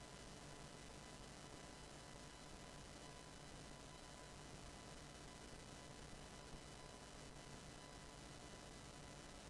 Behringer UMC22 recording noise/electronic buzzing
Onto my problems: when trying to record using Audacity/Cakewalk there is loud white noise/interference (file attached) It is most pronounced when first running my guitar through my amp and then into the UMC22 however it is also present when i plug my guitar directly into the 1/4" jack input or when i plug my shure SM58 into the xlr input.